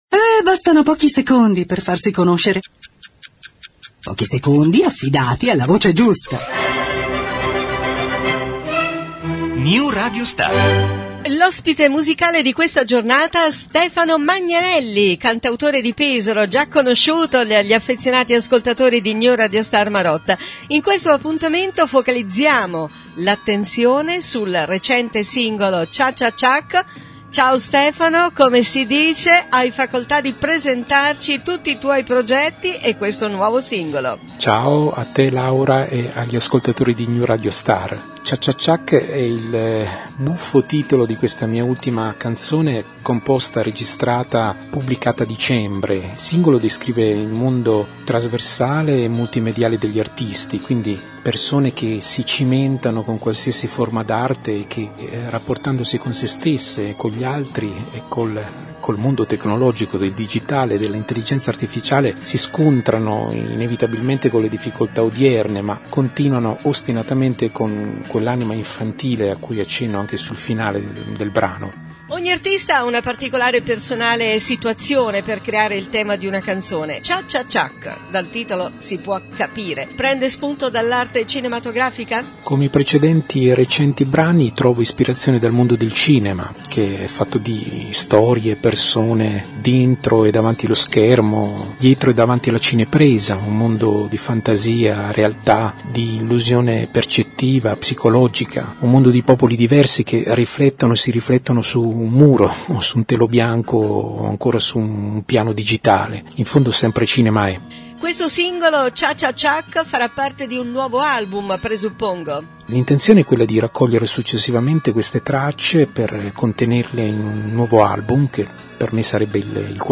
audio intervista e canzone